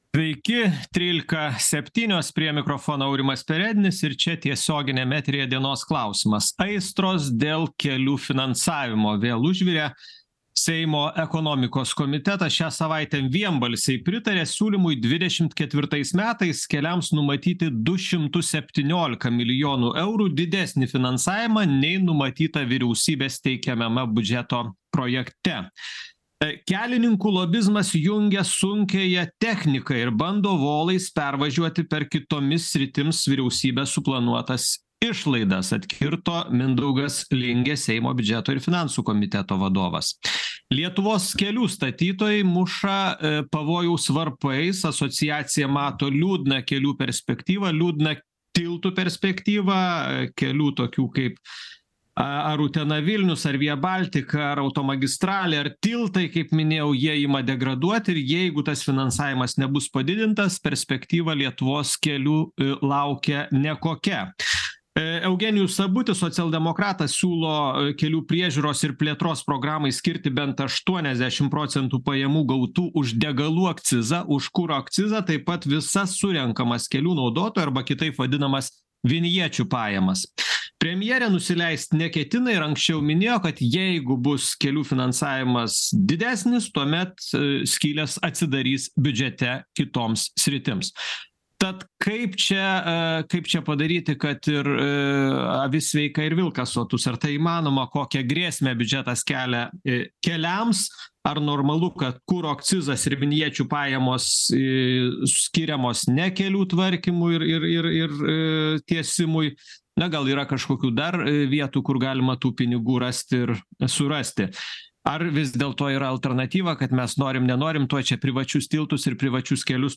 Diskutuoja